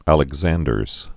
(ălĭg-zăndərz)